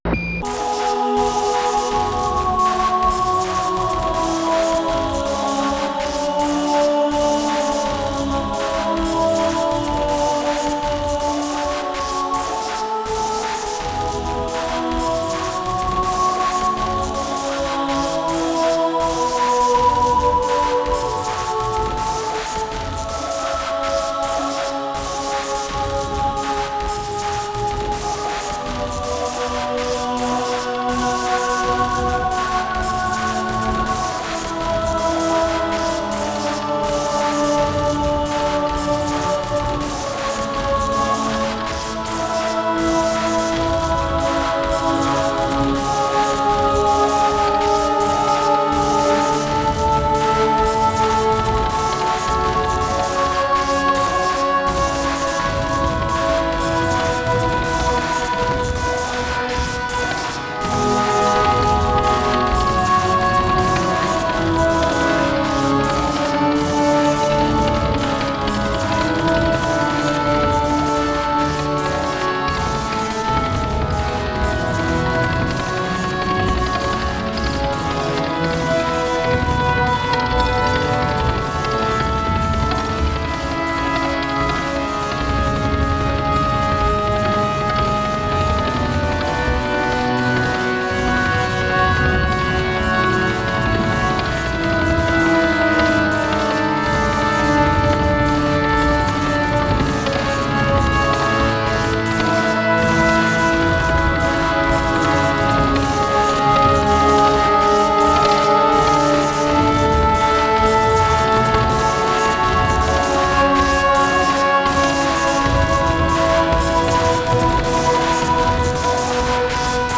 合唱、
音声モーフィング、
オルガン、
ハードコア・ビート、
不整動パンニング、
非実存ギターによるパーカッシブ・タッピング、